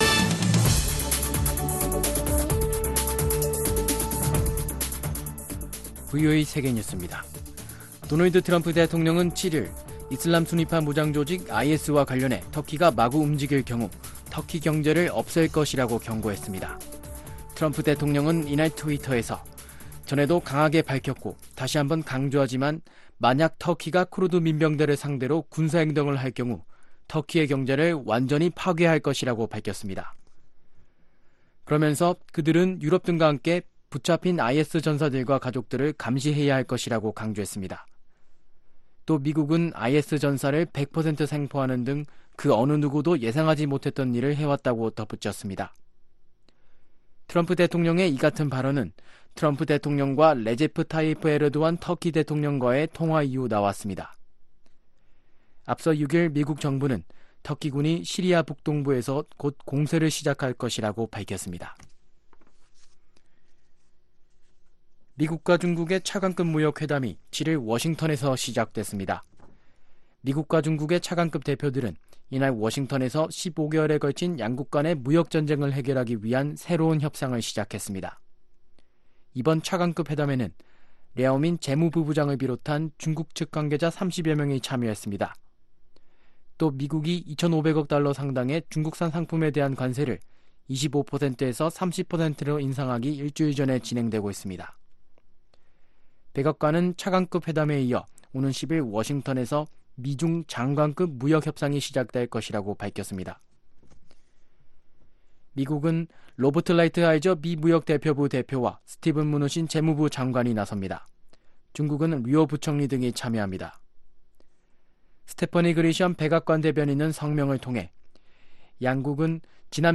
VOA 한국어 아침 뉴스 프로그램 '워싱턴 뉴스 광장' 2019년 10월 8일 방송입니다. 미국과 북한이 하노이 정상회담 이후 7개월만에 다시 만났지만 다시 이견만 확인한 채 실무협상을 끝냈습니다. 어떤 비핵화 협상을 해도 북한은 언제나 잠재적 핵보유국으로 남을 것이라고 로버트 갈루치 전 국무부 북 핵 특사가 말했습니다.